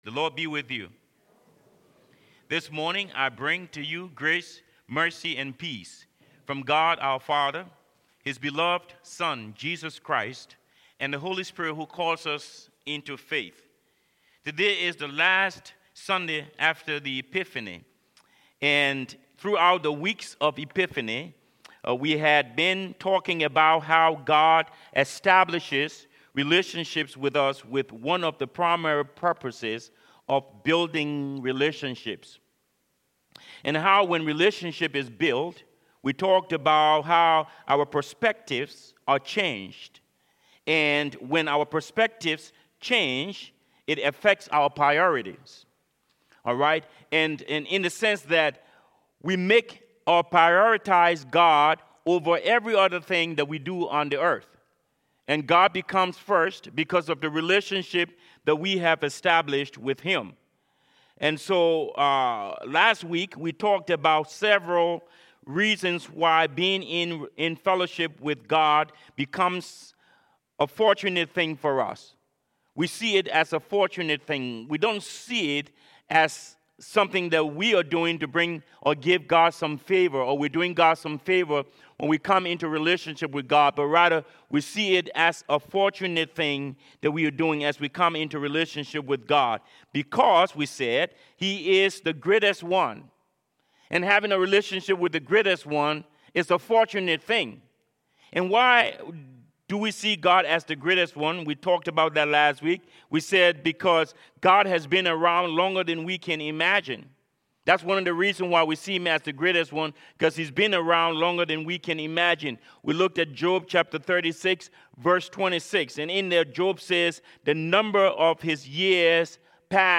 Fifth Sunday after the Epiphany
Service Type: Sermons